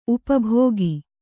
♪ upa bhōgi